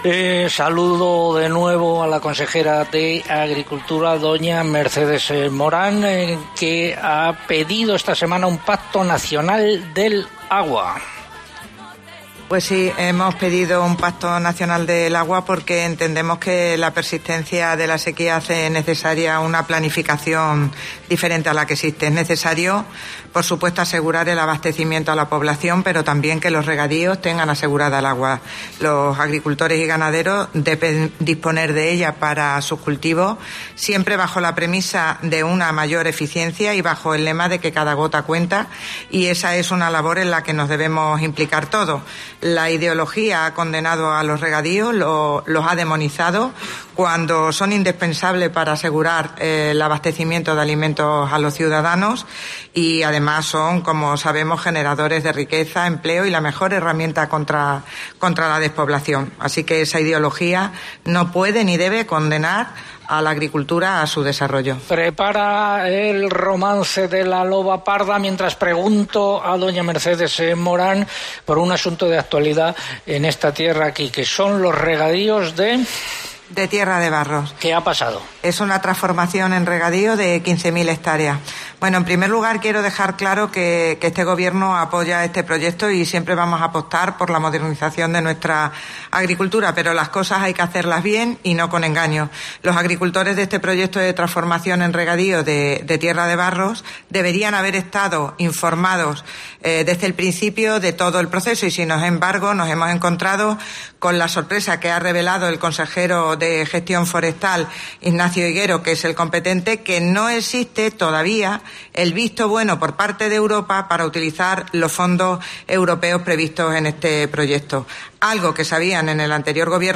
La consejera de Agricultura se refería así a la necesidad de acordar un Pacto Nacional por el Agua. Lo ha dicho en una entrevista en 'Agropopular' que hoy se ha emitido desde Feval
Lo ha dicho durante la entrevista realizada en el programa Agropopular que la Cadena Cope ha emitido esta mañana desde Agroexpo, en Feval, Don Benito.